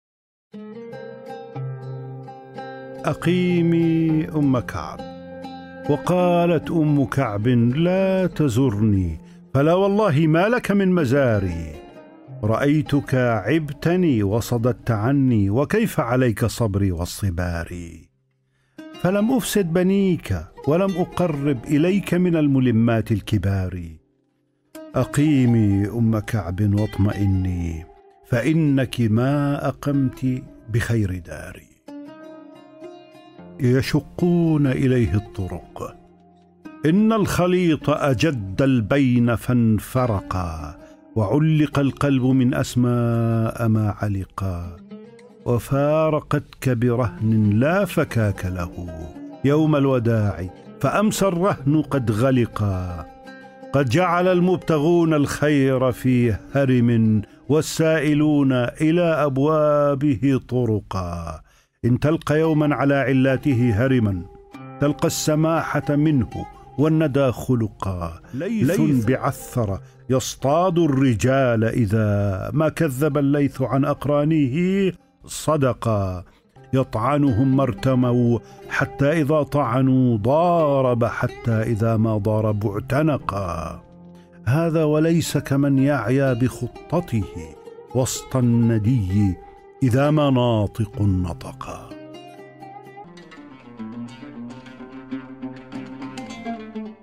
الكتب المسموعة